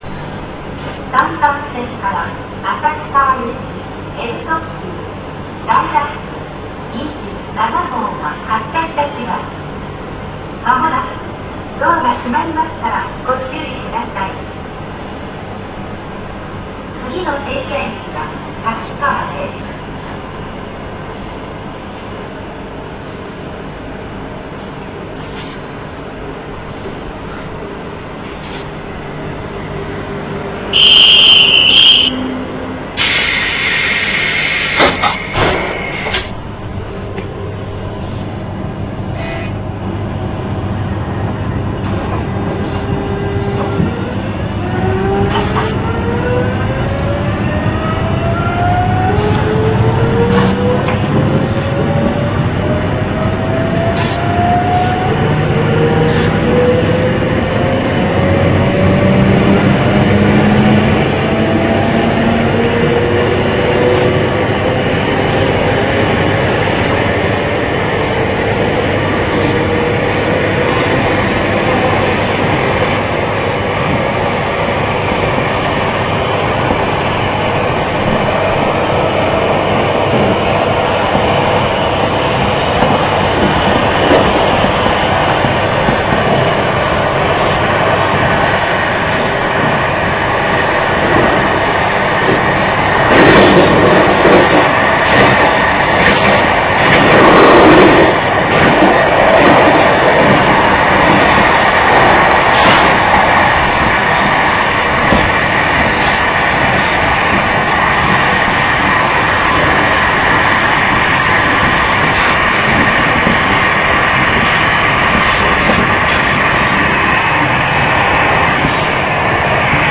走行音[785a.ra/RealAudio5.0形式/542KB]
収録区間：函館本線 砂川→滝川(ライラック27号にて)
制御方式：VVVFインバータ制御(日立GTO初期型)
※1駅区間まるごと収録してあります。6分ちょっとあります。